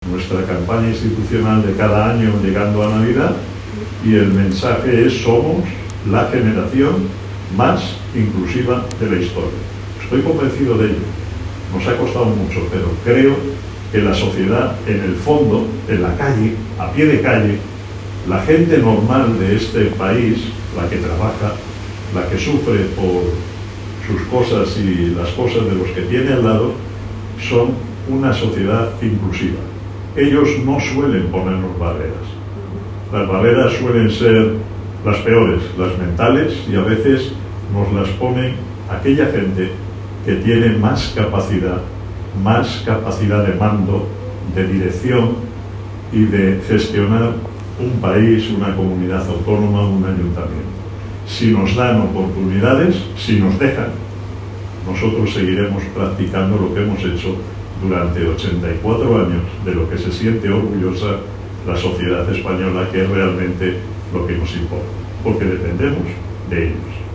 ante algunos medios de comunicación la mañana del 13 de diciembre.